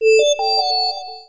CallConnect_M.wav